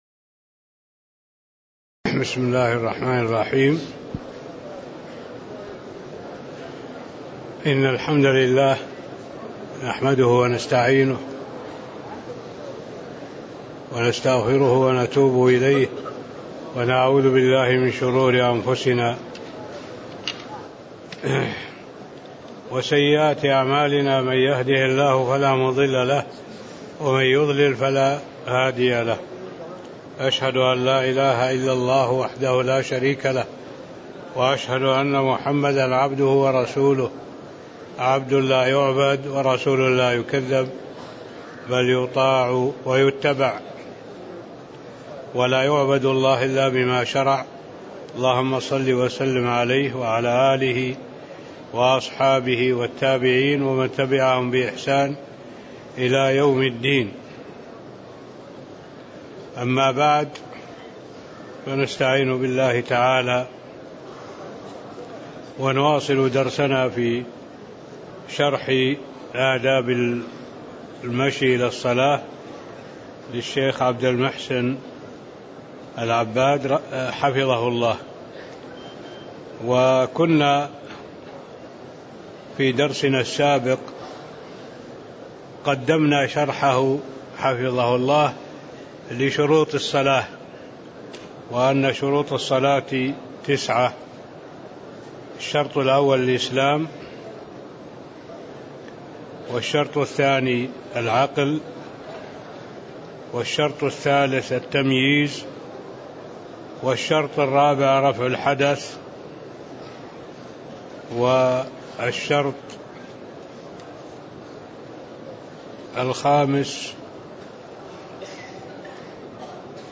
تاريخ النشر ٢٤ ذو الحجة ١٤٣٥ هـ المكان: المسجد النبوي الشيخ: معالي الشيخ الدكتور صالح بن عبد الله العبود معالي الشيخ الدكتور صالح بن عبد الله العبود أركان الصلاة (03)